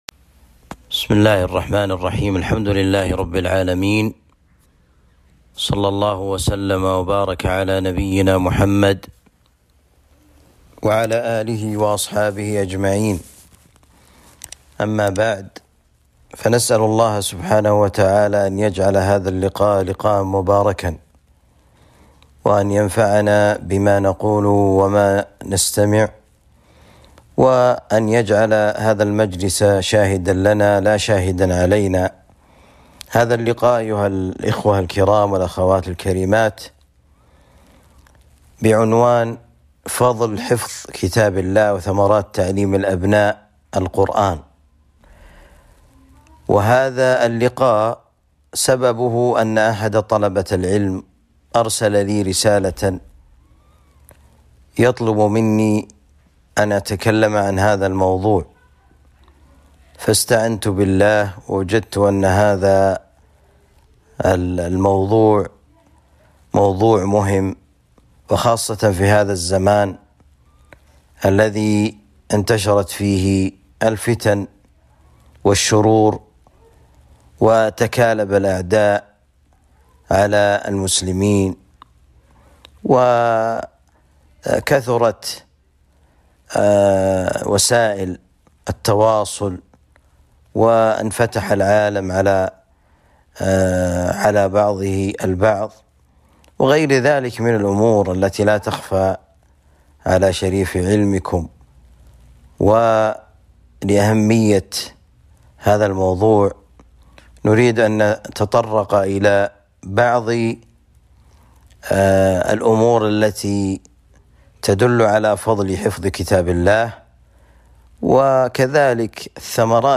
كلمة ونصيحة